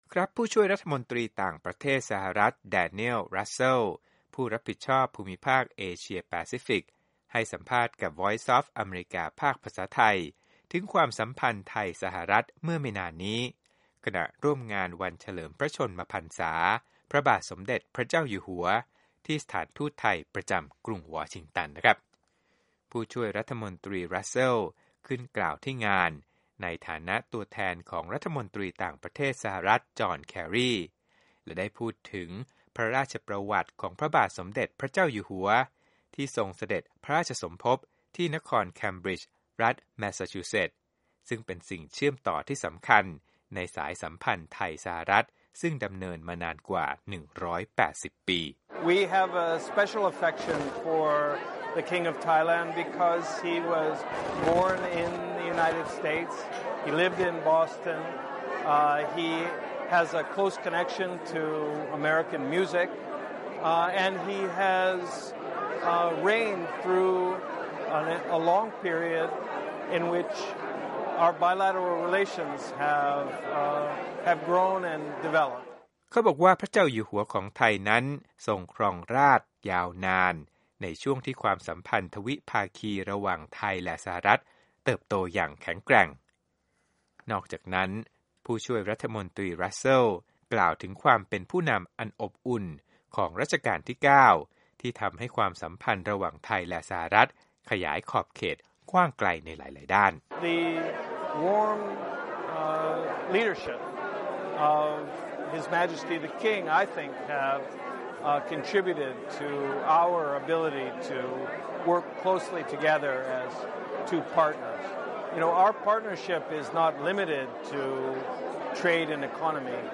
ผู้ช่วย รมต.รัสเซล เป็นตัวแทนรัฐบาลสหรัฐฯ ที่ร่วมงานวันเฉลิมพระชนมพรรษาที่สถานเอกอัครราชทูตไทย ณ กรุงวอชิงตัน
Interview Daniel Russel